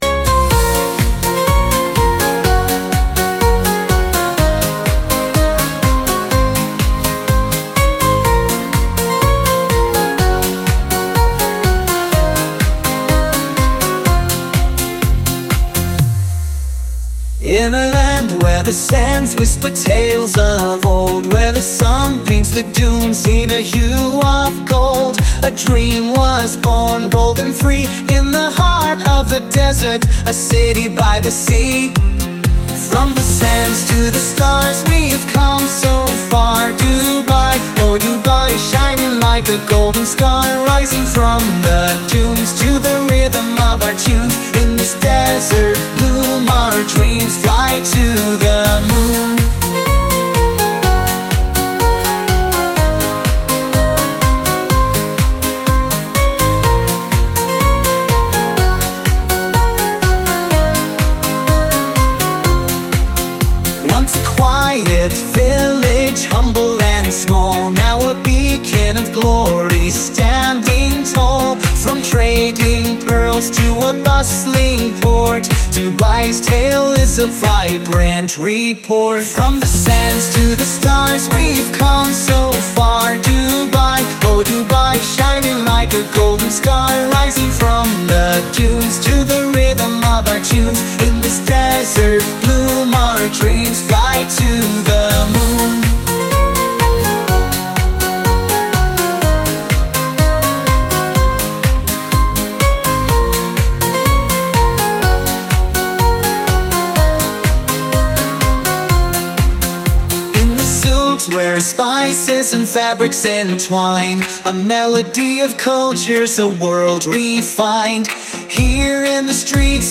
KI-Musik